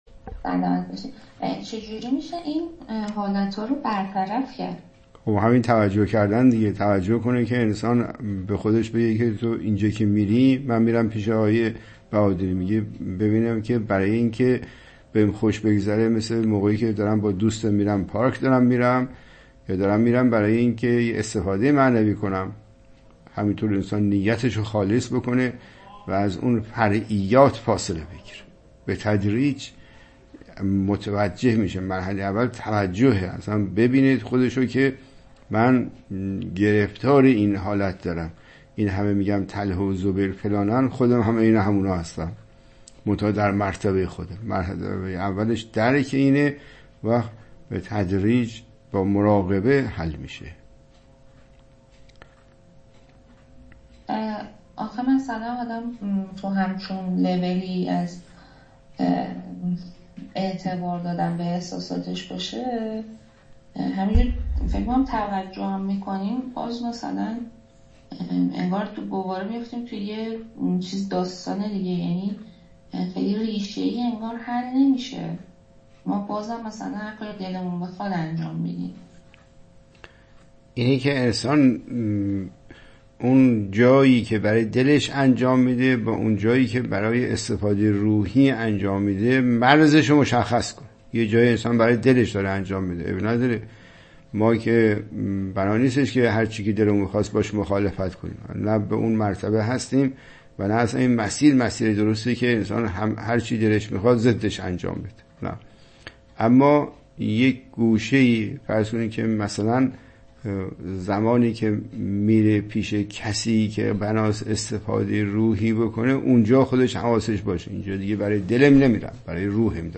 متن : گفت‌وگو